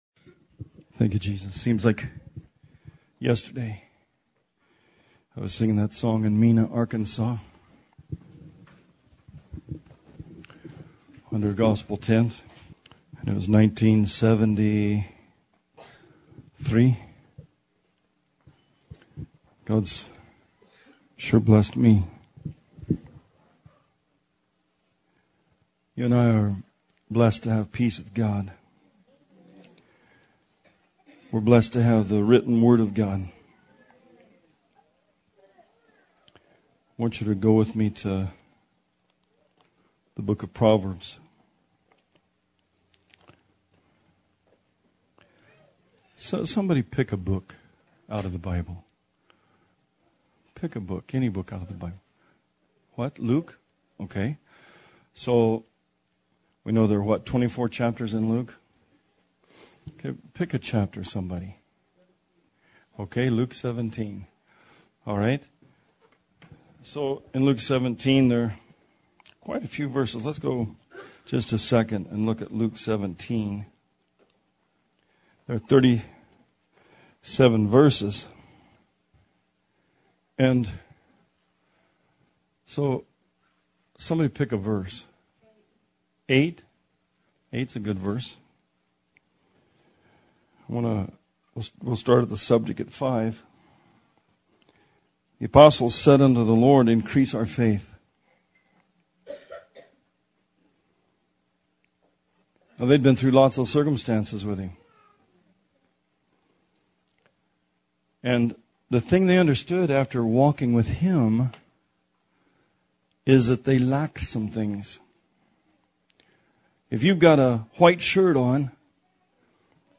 Series Sermon